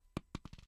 drop_apple.ogg